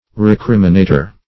Recriminator \Re*crim"i*na`tor\ (-n?`t?r), n.
recriminator.mp3